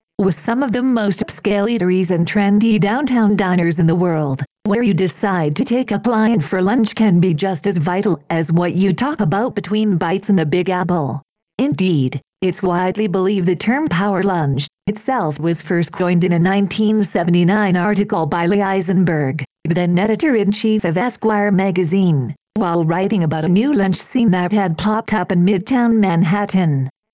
Voice Demo
Cepstral Allison 16k (U.S. English)